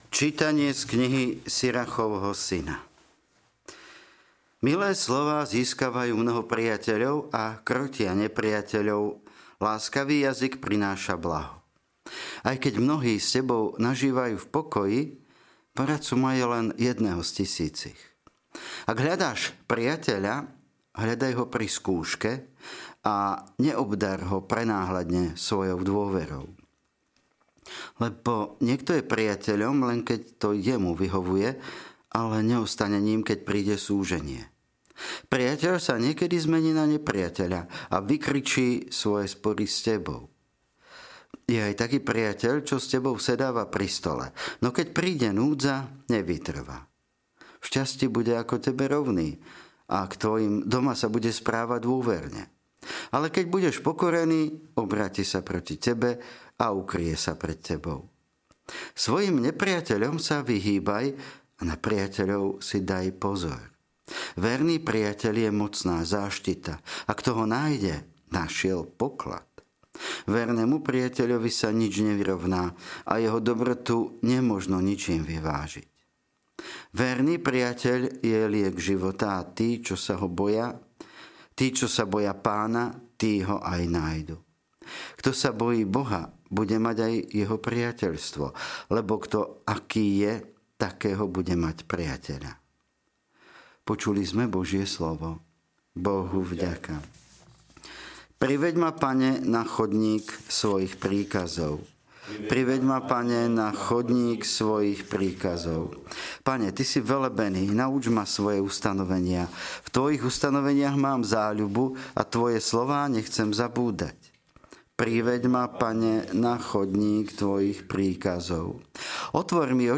LITURGICKÉ ČÍTANIA | 28. februára 2025